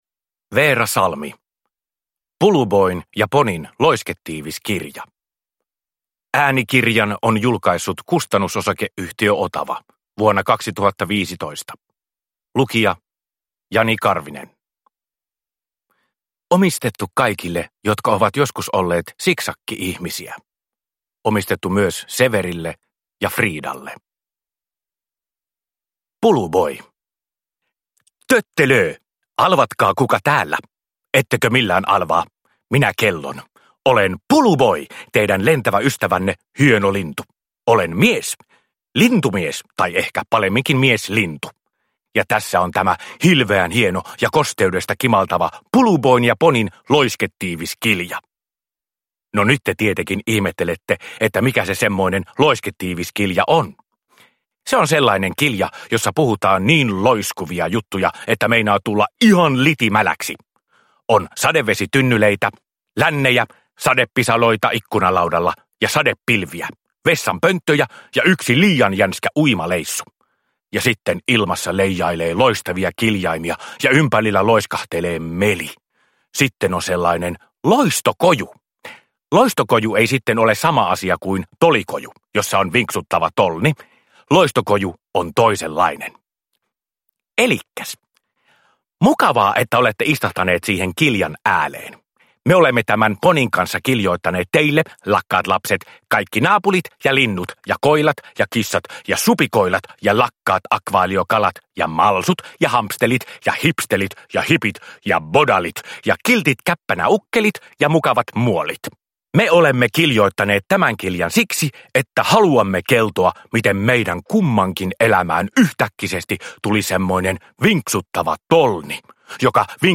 Puluboin ja Ponin loisketiivis kirja – Ljudbok